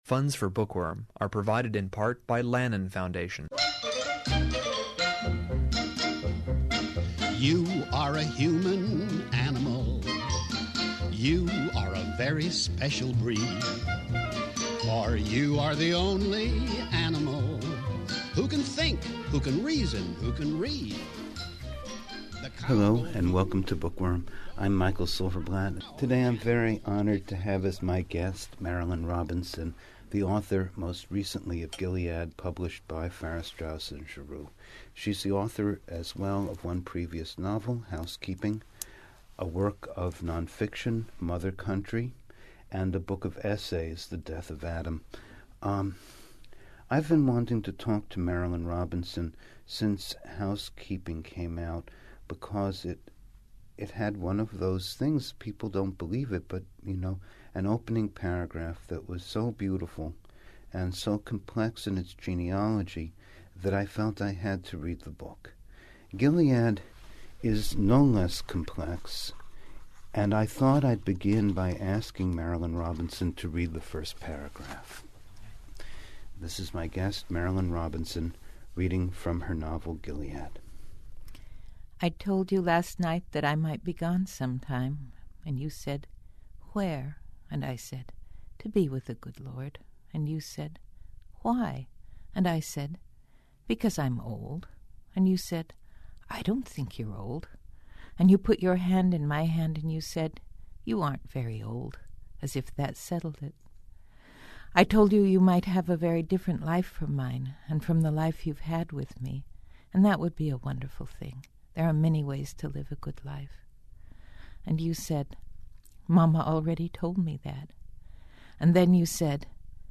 In this first of a two-part conversation, we discuss her…